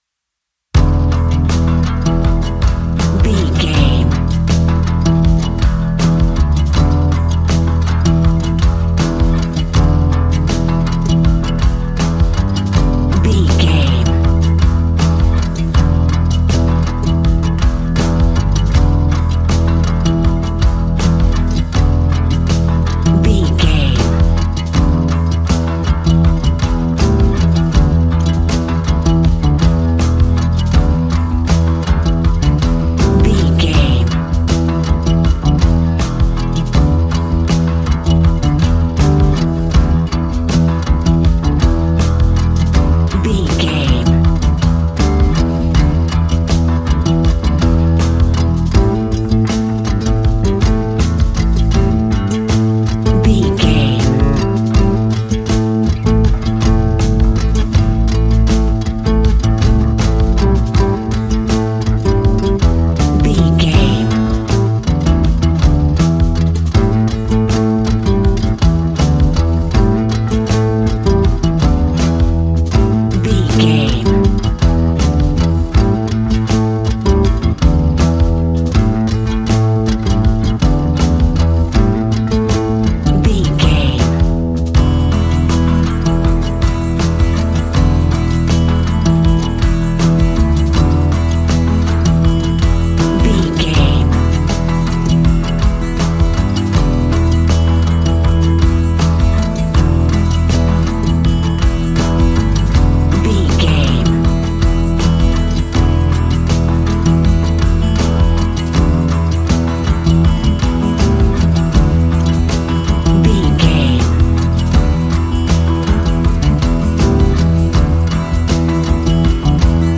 Aeolian/Minor
romantic
sweet
happy
acoustic guitar
bass guitar
drums